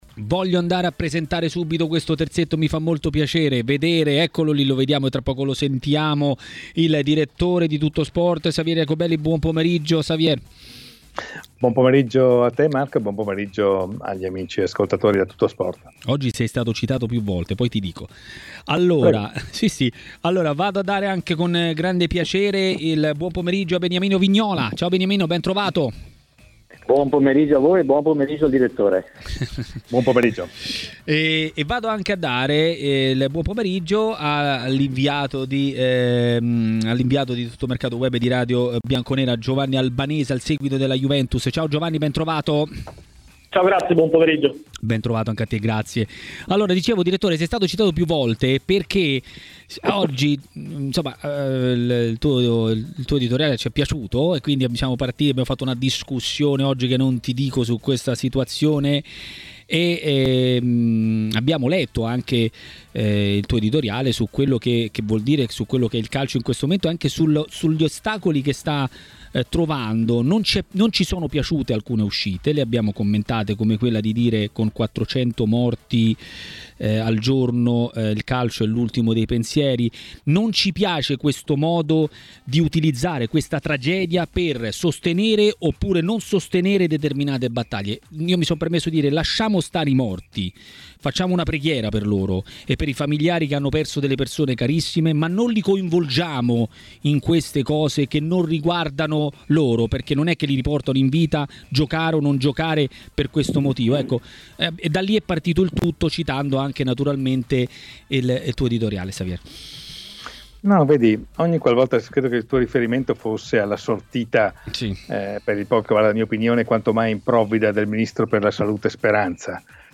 Beniamino Vignola, ex calciatore della Juventus, interviene durante la trasmissione “Maracanà” sulle frequenze di TMW Radio.